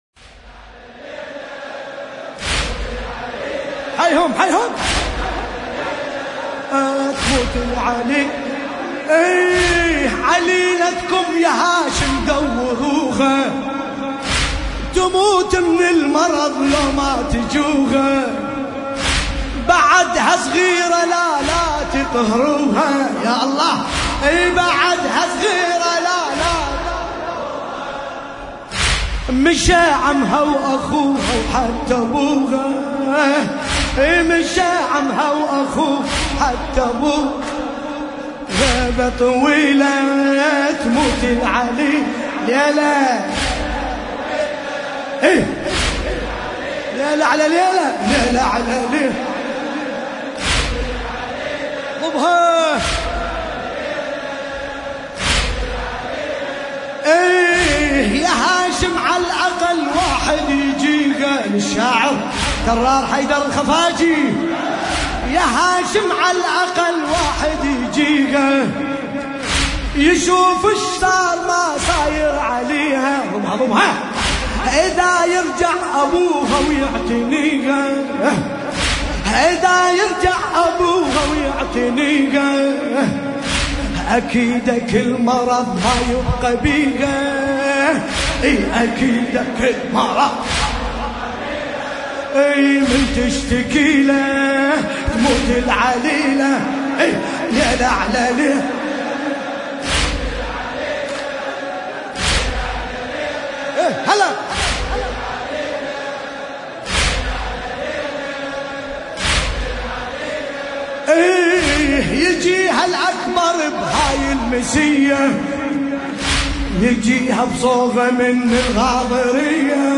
قصيدة : ليلة اعله ليلة...اتموت العليلة
الرادود : الحاج ملا باسم الكربلائی